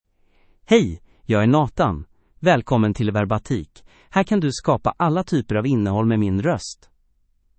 NathanMale Swedish AI voice
Nathan is a male AI voice for Swedish (Sweden).
Voice sample
Male
Nathan delivers clear pronunciation with authentic Sweden Swedish intonation, making your content sound professionally produced.